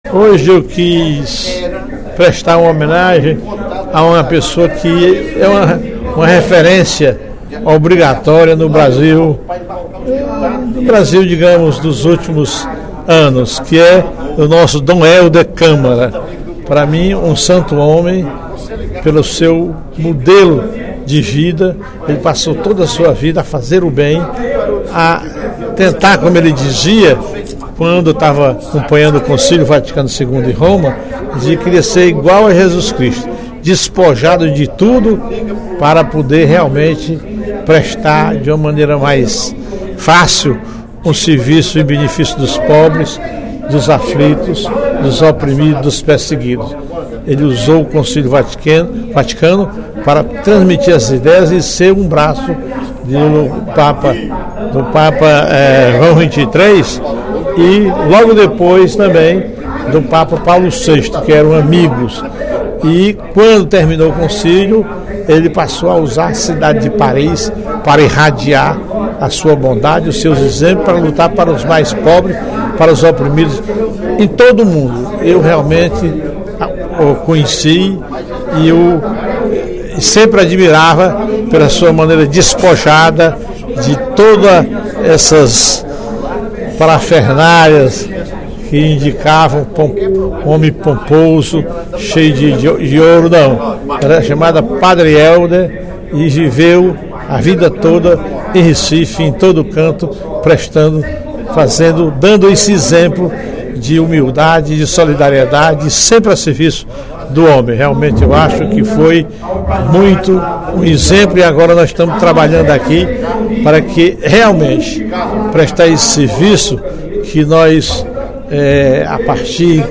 No primeiro expediente da sessão plenária desta sexta-feira (10/04), o deputado Professor Teodoro (PSD) comemorou o início do processo de beatificação de Dom Helder Câmara pela Igreja Católica.